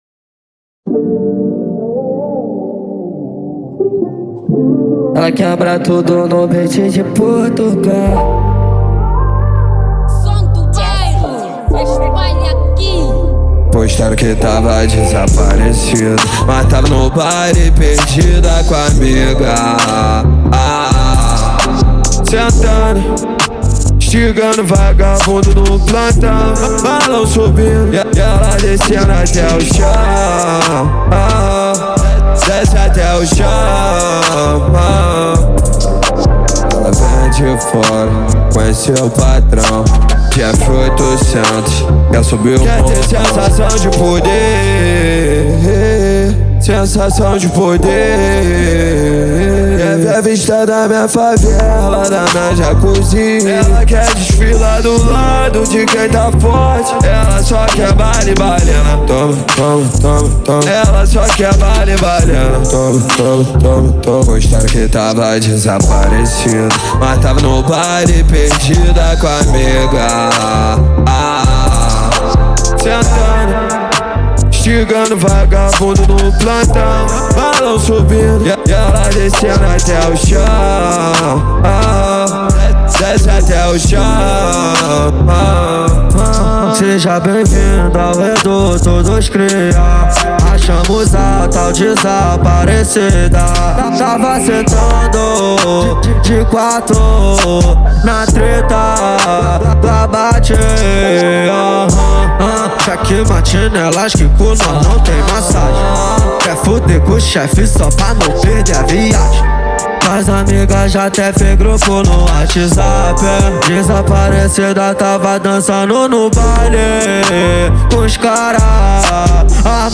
Genero: RAP